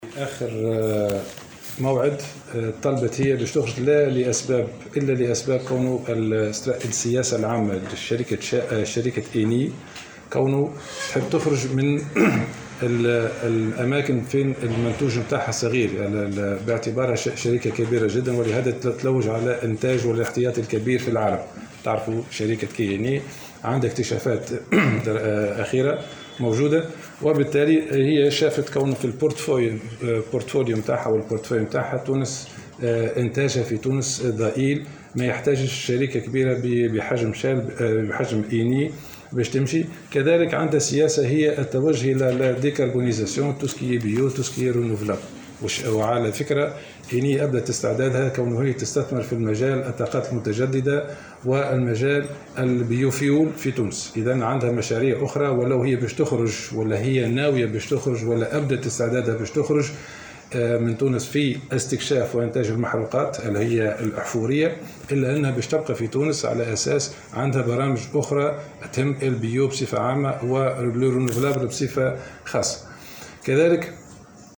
أكد مدير عام المحروقات بوزارة الصناعة، رشيد بن دالي، خلال ندوة صحفية اليوم، أن شركة "ايني" أبدت نيتها في التخلي عن التنقيب والاستكشاف في تونس نظرا لضعف انتاجها وسعيها للاستثمار في الطاقات المتجددة في تونس.